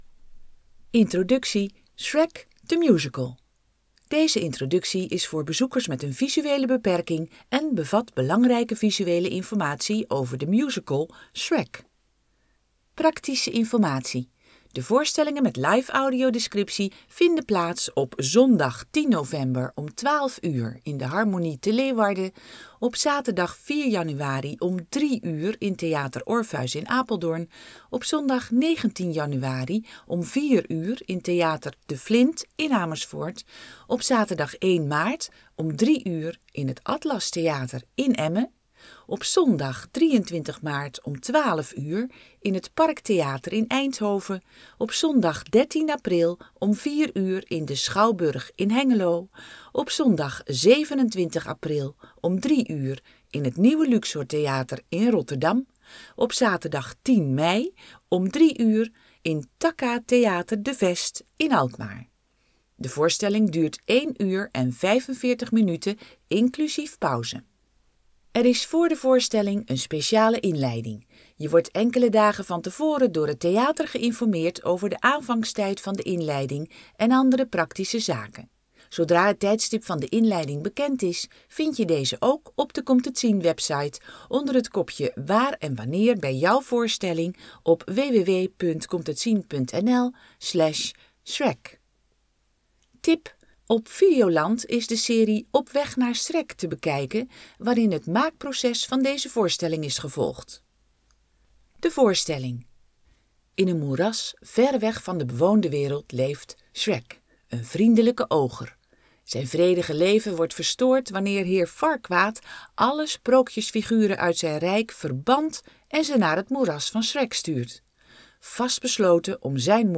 Audiodescriptie door blindentolken, live voor theater, evenementen, festivals en uitjes
Introductie Shrek de Musical Word